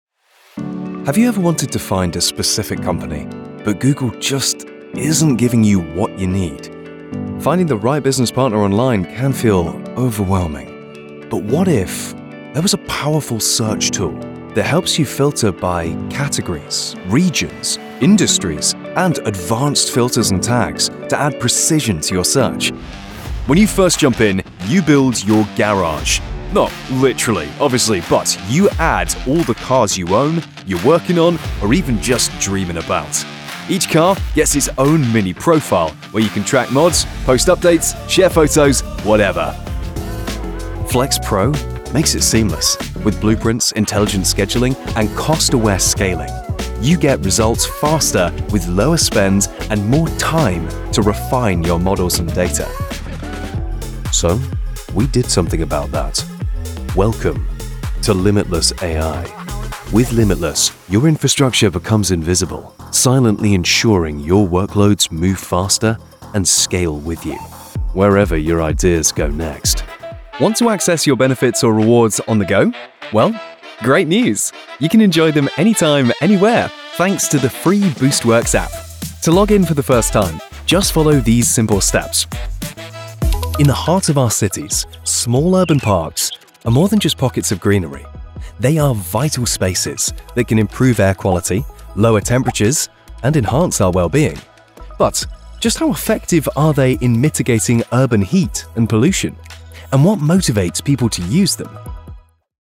Commerciale, Naturelle, Amicale, Chaude, Polyvalente
Vidéo explicative